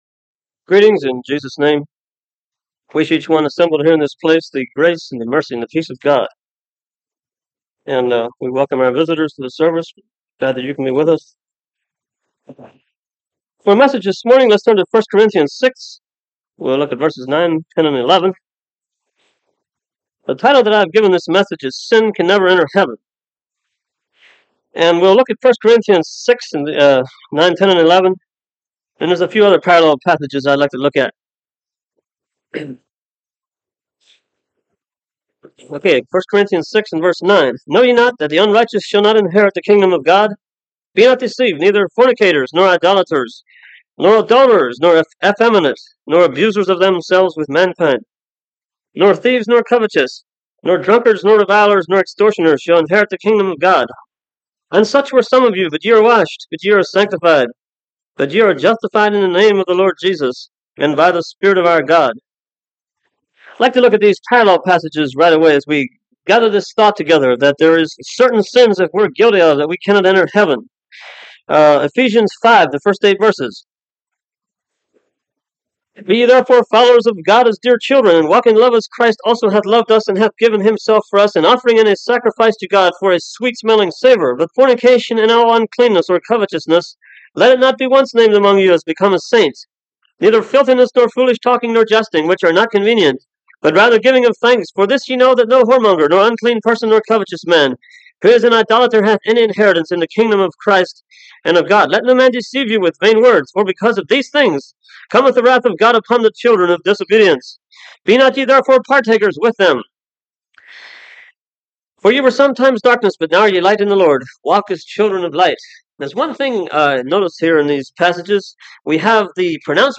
Passage: 1 Corinthians 6:9-11 Service Type: Sunday Morning Topics